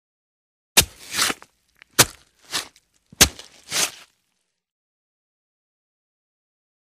Stab Flesh
Knife Into Flesh: In / Out ( 3x ); Three Quick, Mushy, Sloppy Knife Impacts And Pull Outs. Close Perspective.